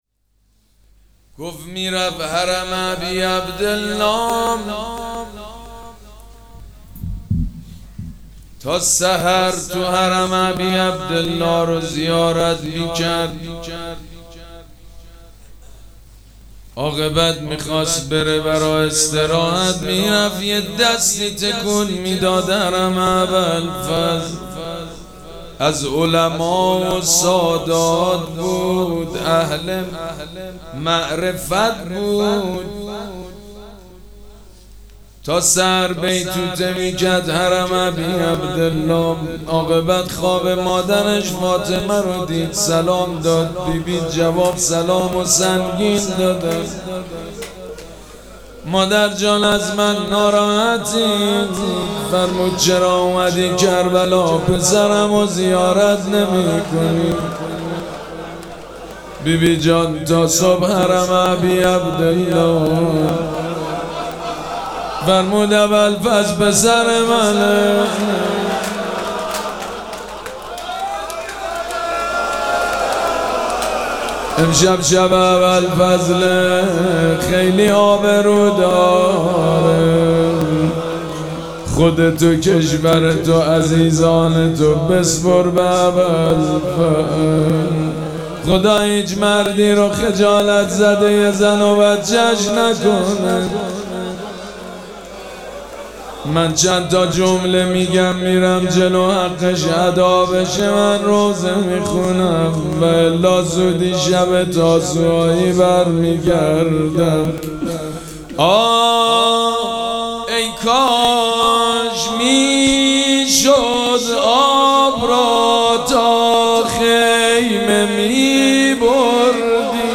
مراسم عزاداری شب نهم محرم الحرام ۱۴۴۷
روضه
حاج سید مجید بنی فاطمه